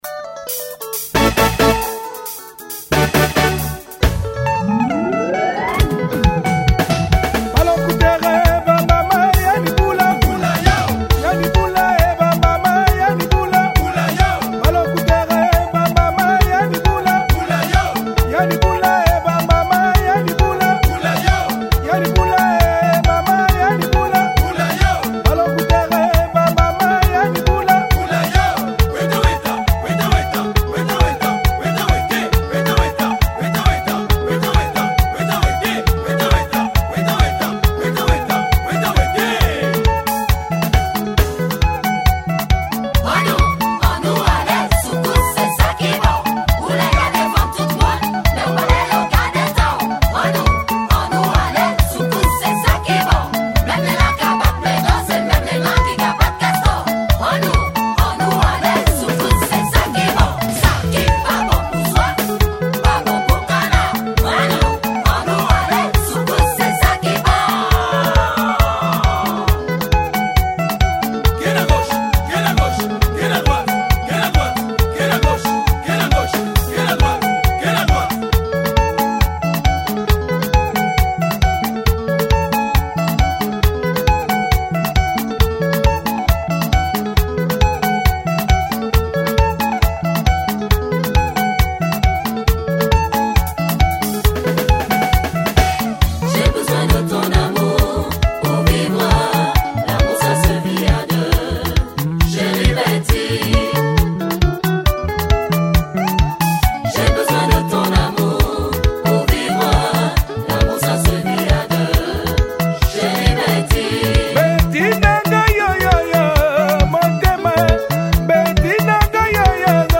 Congolese Soukous